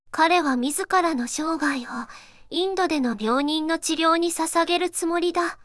voicevox-voice-corpus / ita-corpus /四国めたん_セクシー /EMOTION100_032.wav